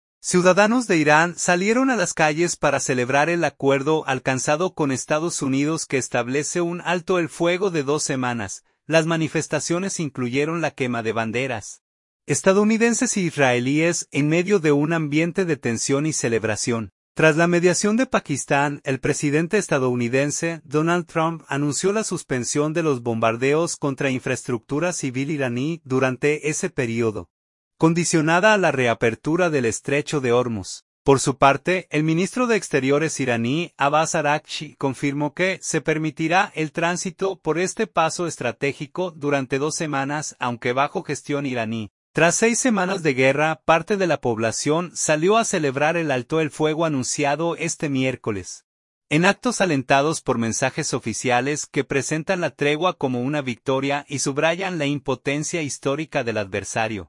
Ciudadanos de Irán salieron a las calles para celebrar el acuerdo alcanzado con Estados Unidos que establece un alto el fuego de dos semanas. Las manifestaciones incluyeron la quema de banderas estadounidenses e israelíes, en medio de un ambiente de tensión y celebración.